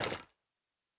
Foley Armour 02.wav